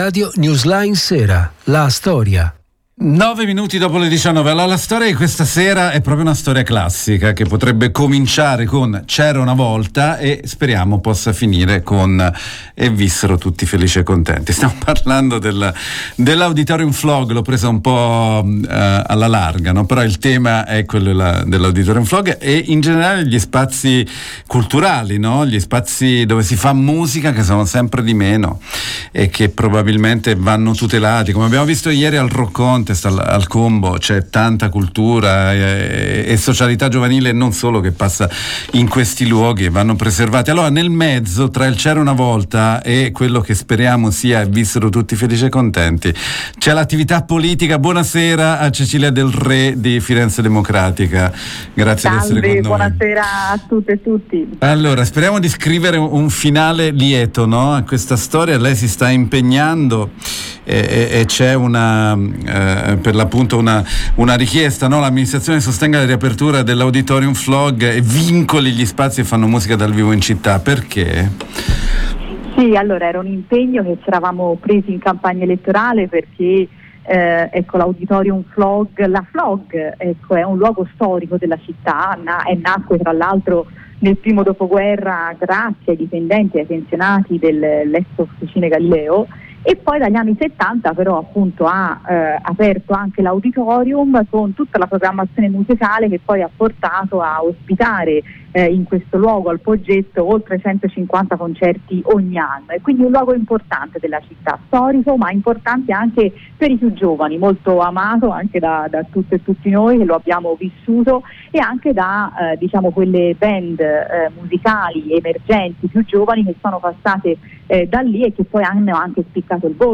Cecilia Del Re“In campagna elettorale ci eravamo presi l’impegno di sostenere la riapertura di questo spazio storico della città, e di valorizzare tutti gli spazi che fanno musica dal vivo” dice Del Re, l’abbiamo intervistata